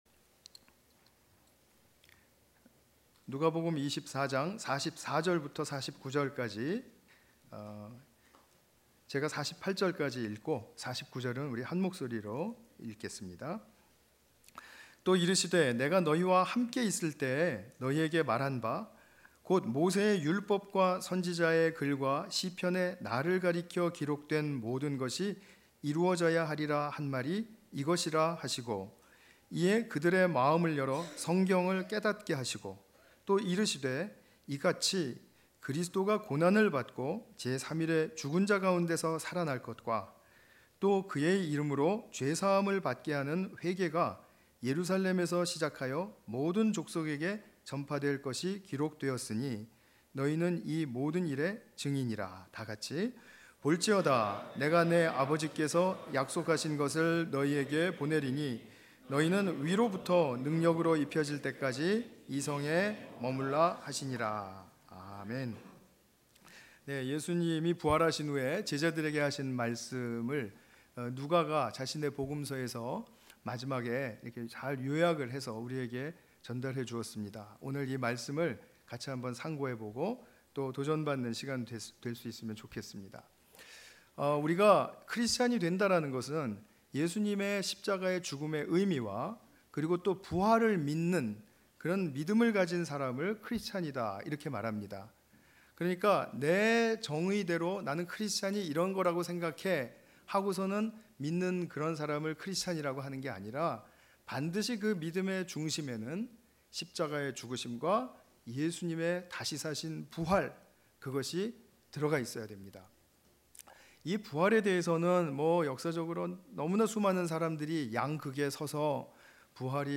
관련 Tagged with 주일예배